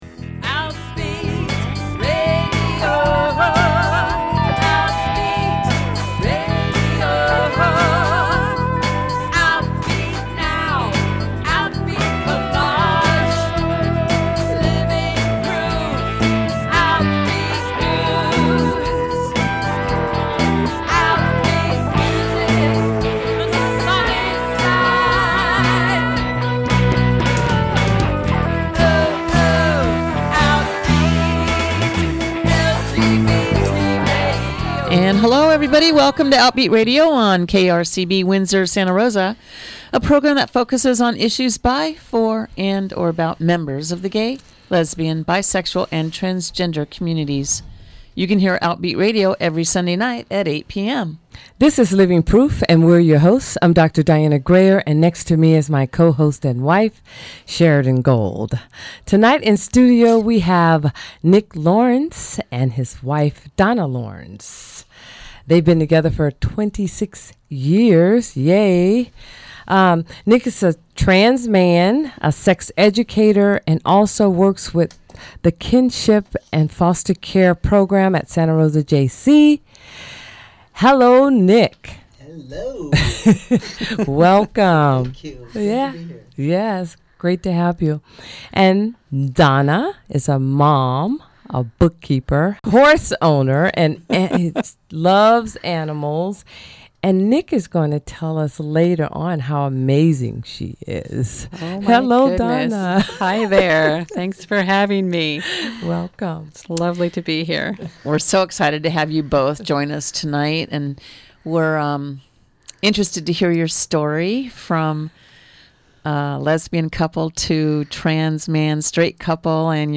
Outbeat Radio is a weekly radio program for and about the lesbian, gay, bisexual and transgender community in the California North Bay airing on KRCB Radio 91.1 FM